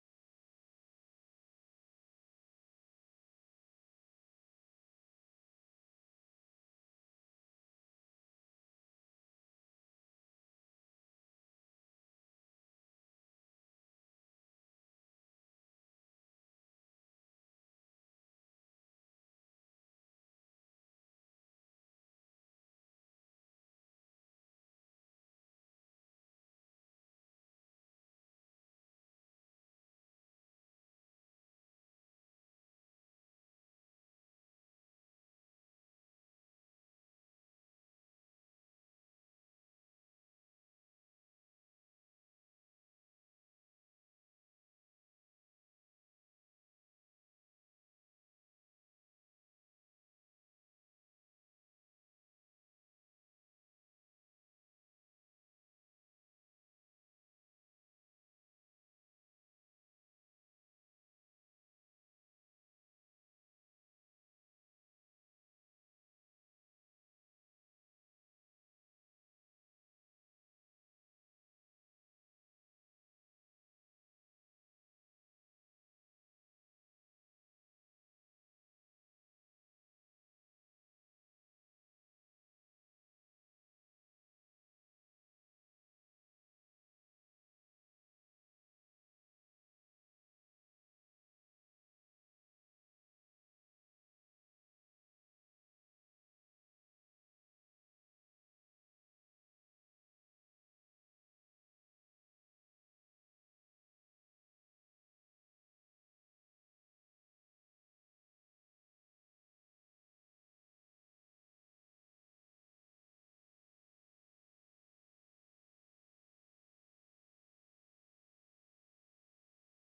Resumo (14ª Sessão Ordinária da 4ª Sessão Legislativa da 18ª Legislatura)
Tipo de Sessão: Sessão Ordinária